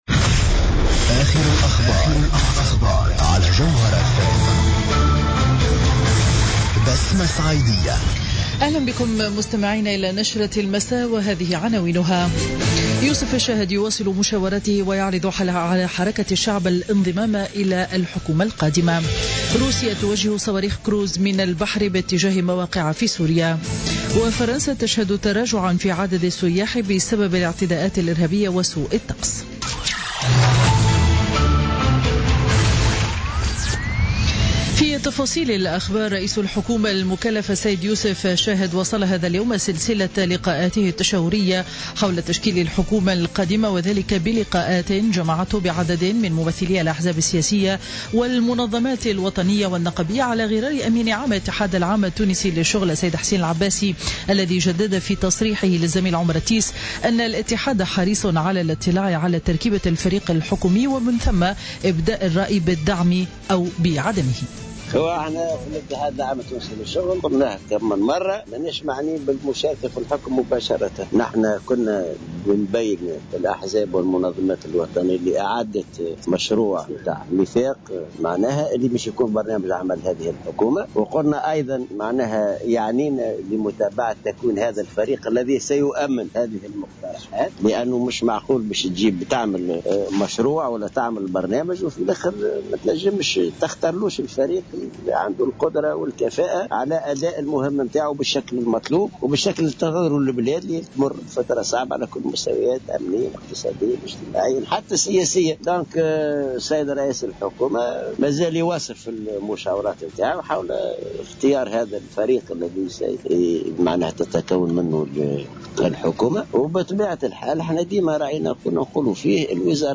نشرة أخبار السابعة مساء ليوم الجمعة 19 أوت 2016